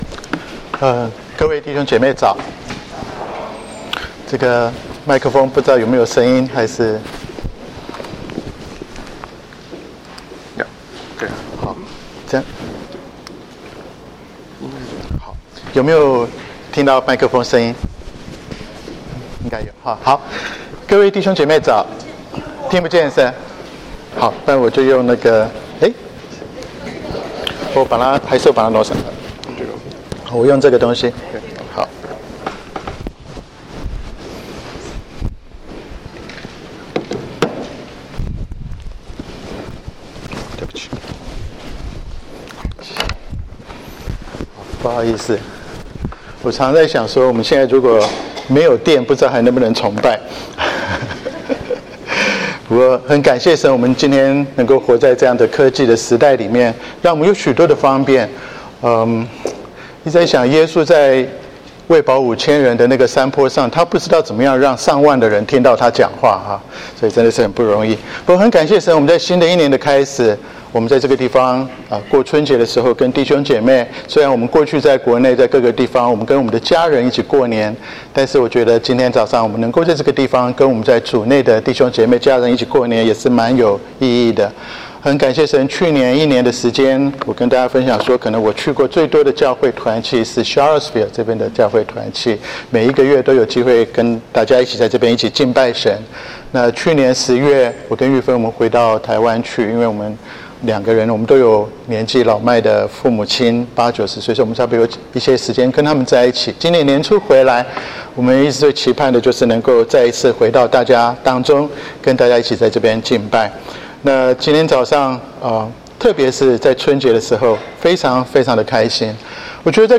2017 在主裡配搭事奉 證道